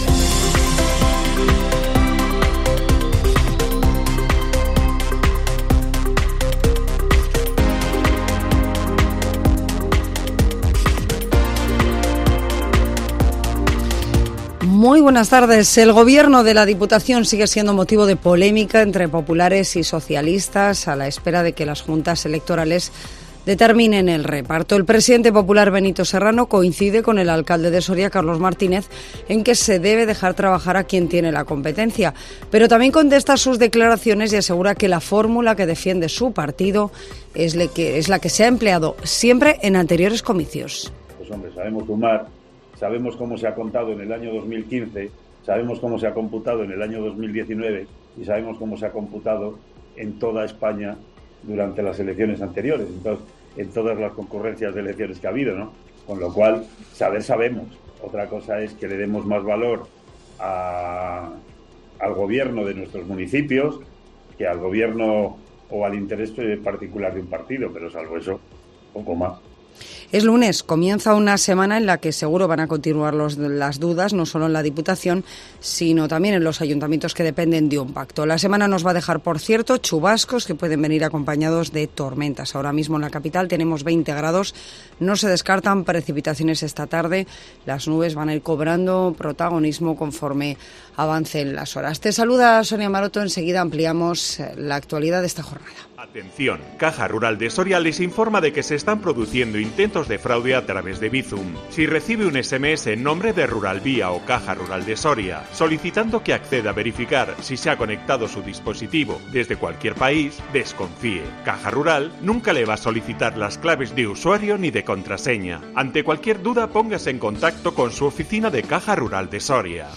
INFORMATIVO MEDIODÍA COPE SORIA 5 JUNIO 2023